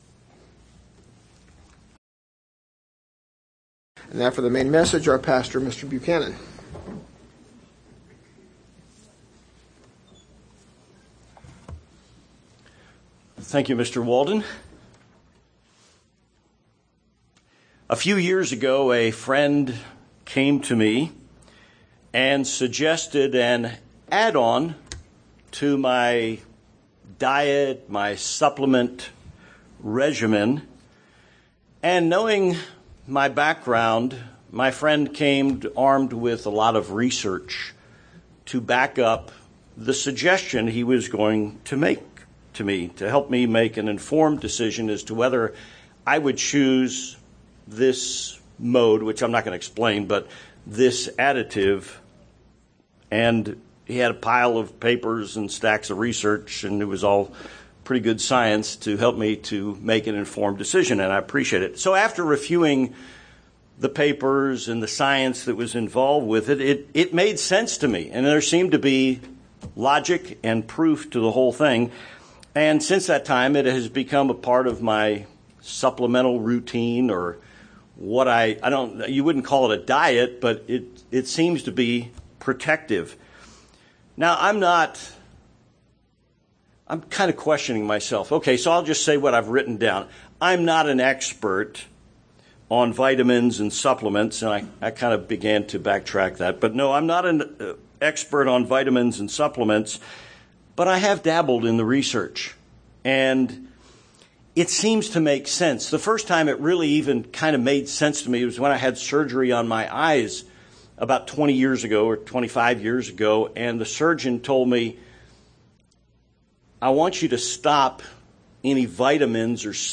The same principle applies to our spiritual defense system. This sermon is designed to boost your spiritual immunity.